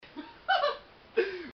Laugh 37